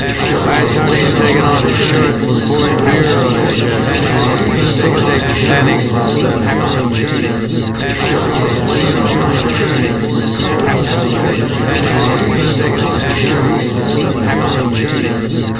In the first, short speech recordings are obscured by a noise which is meant to resemble that in a crowded room with several people talking at the same time.
• In all examples, the speech consists of (slightly slurred) American English, spoken by a man
• All sound files are in the  .wav format (mono)
Part 1: Obscured speech